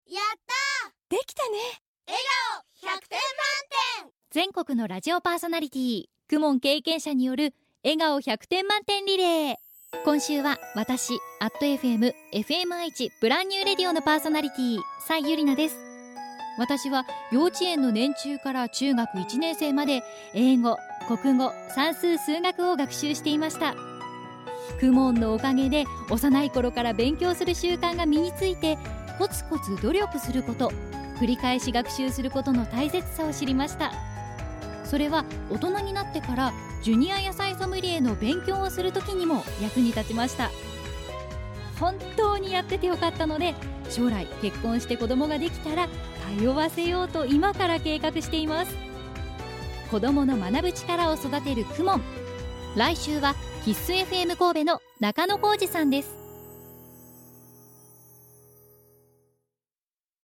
全国のパーソナリティの声